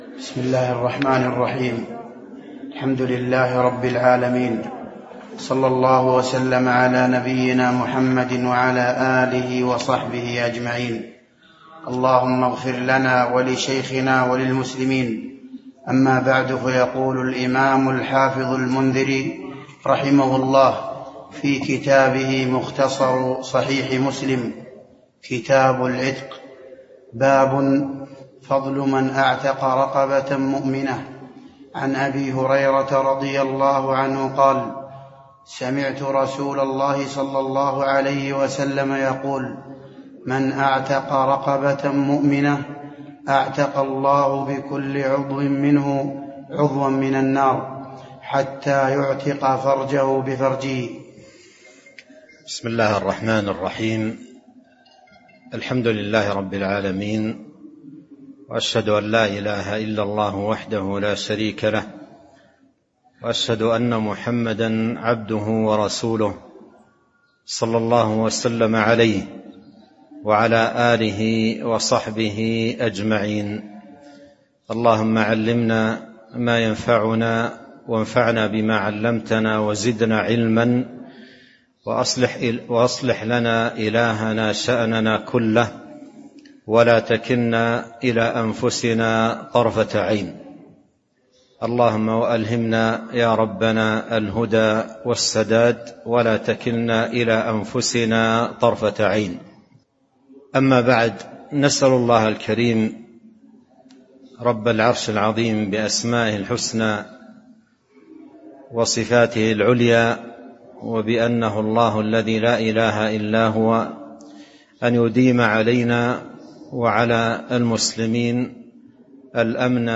تاريخ النشر ٢٥ محرم ١٤٤٣ هـ المكان: المسجد النبوي الشيخ